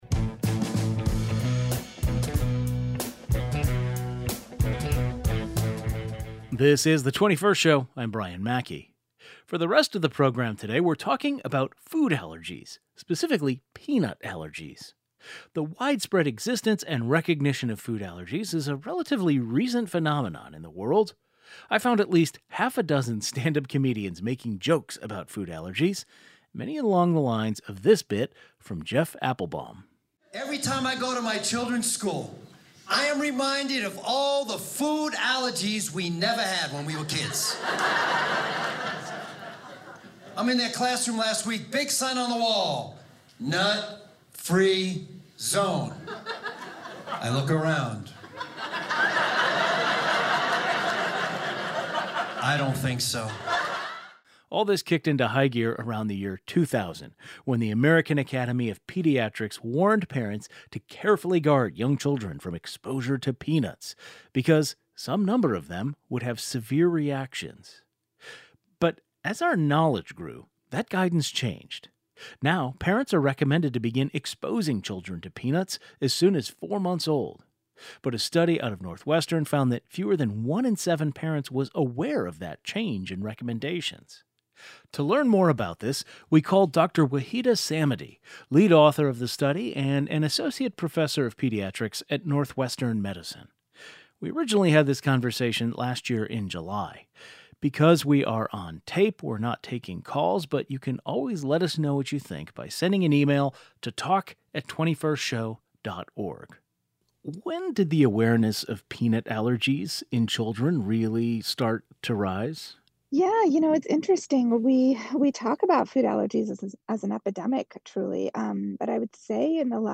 Pediatrician explains why giving infants peanuts may prevent allergies later